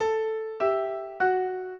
minuet13-2.wav